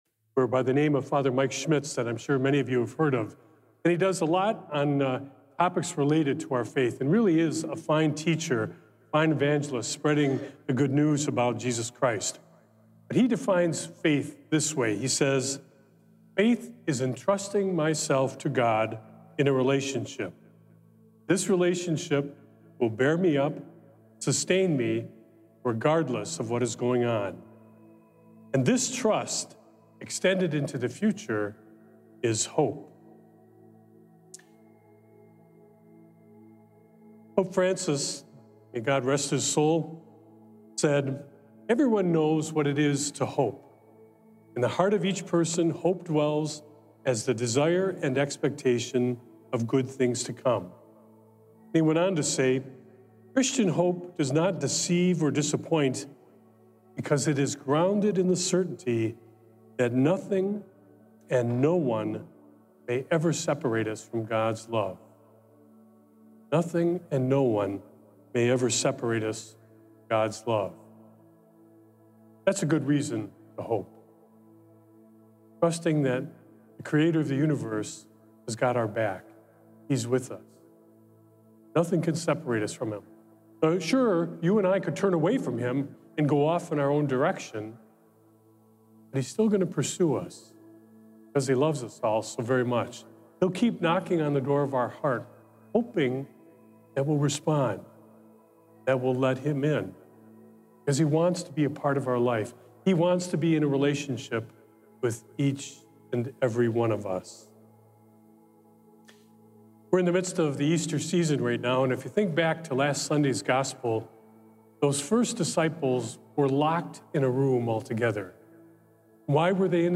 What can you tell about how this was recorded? Recorded Live on Thursday, May 1st, 2025 at St. Malachy Catholic Church.